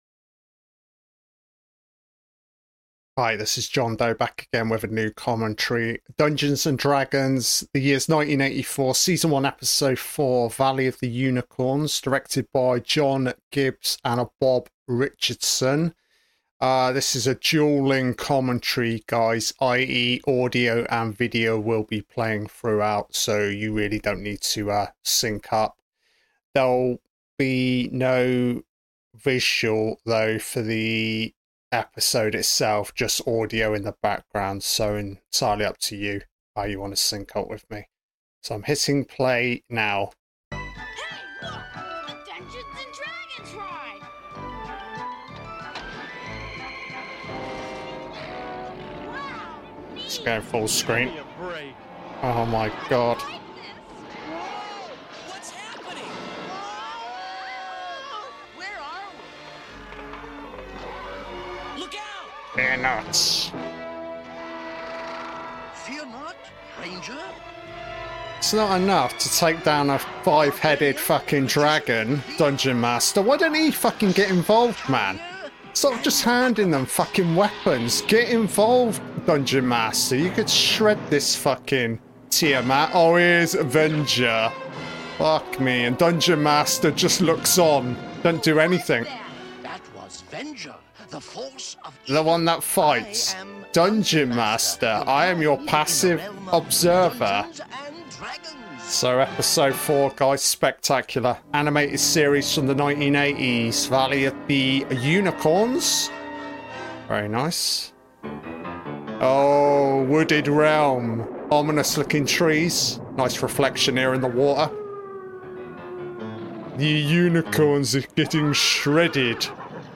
An Audio-Only Commentary on the 1983 TV Series DUNGEONS & DRAGONS